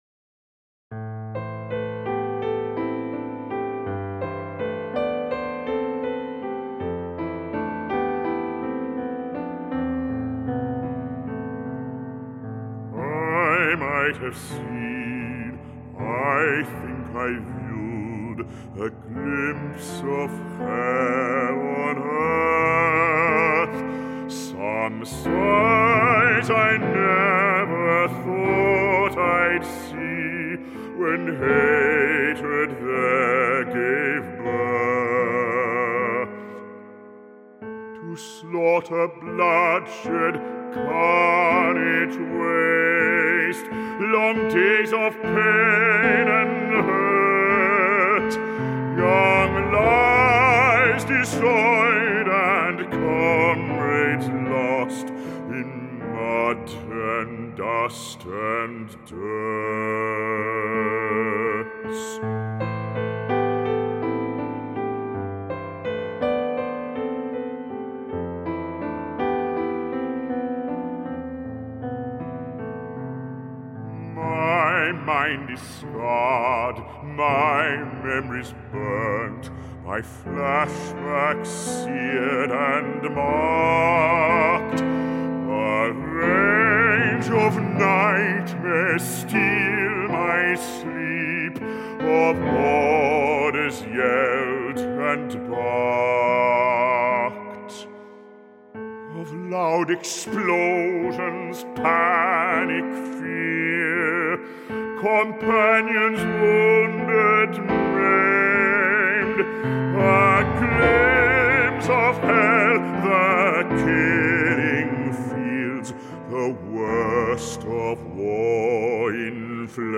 opera singer